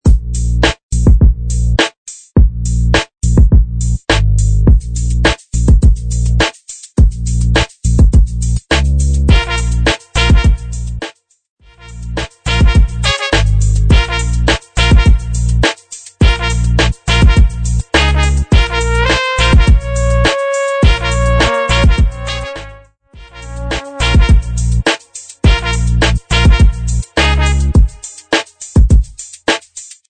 104 BPM
Urban Funk